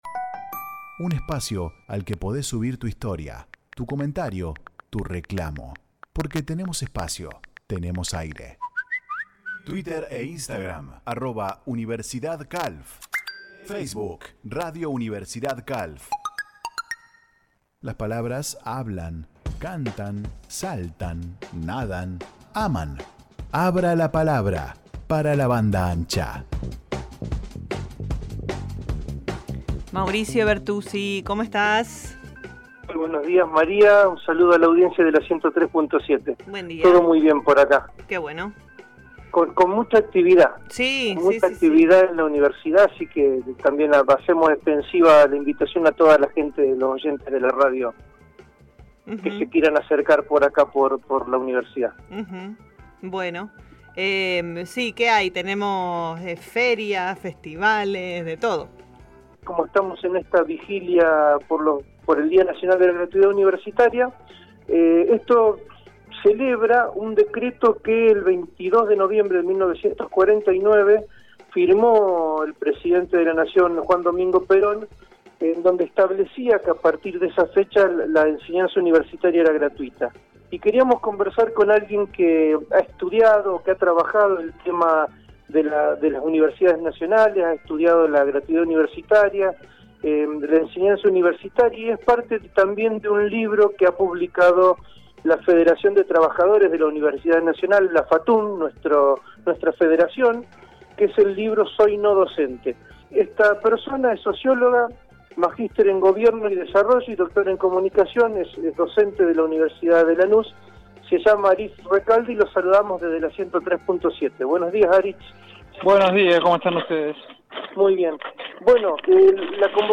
conversamos con el sociólogo